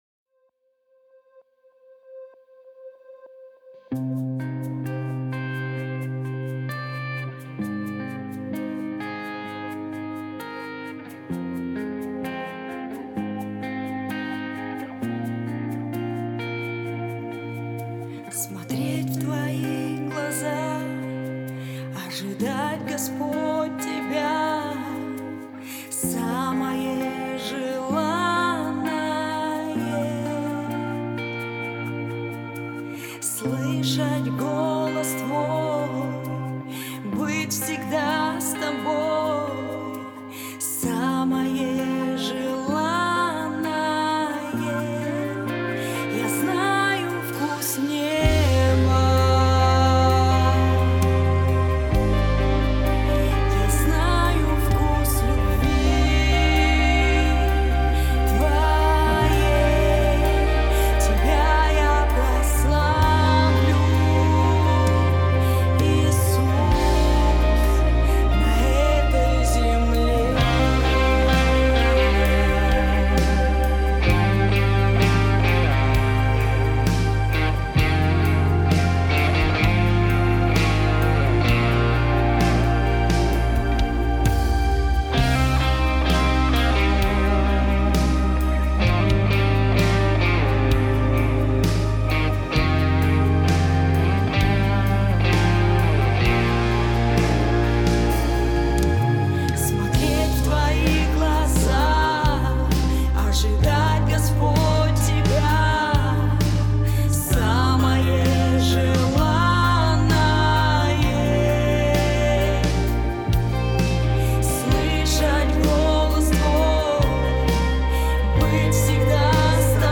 1006 просмотров 435 прослушиваний 73 скачивания BPM: 130